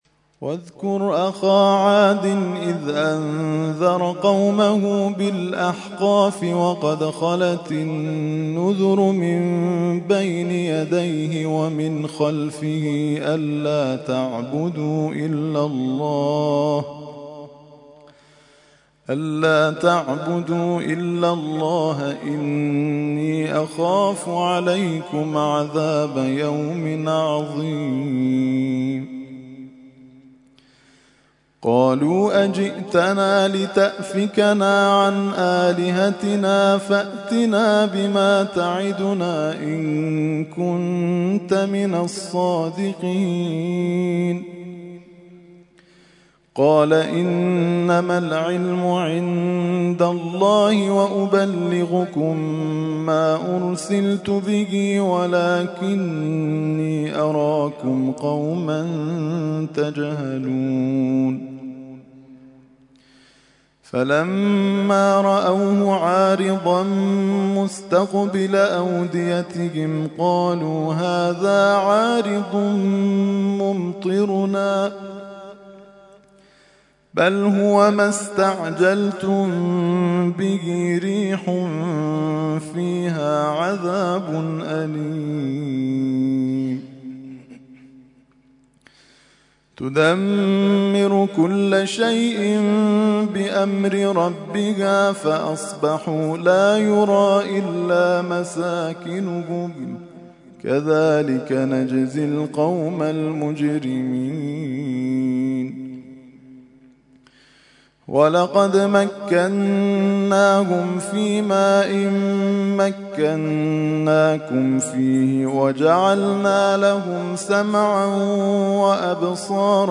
ترتیل خوانی جزء ۲۶ قرآن کریم در سال ۱۳۹۲